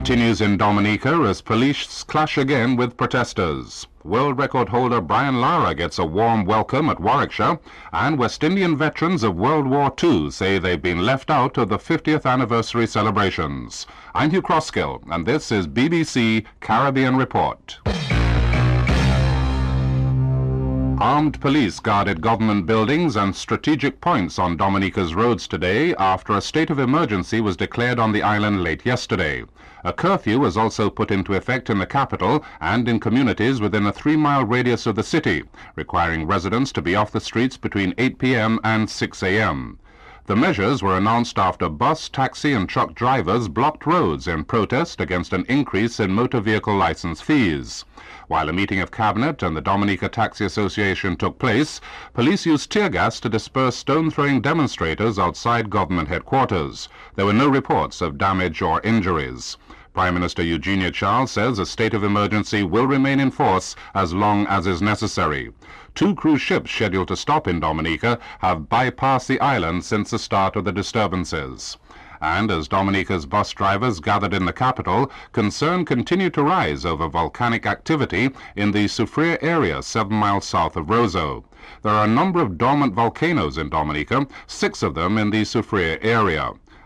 Brian Lara is interviewed during the segment.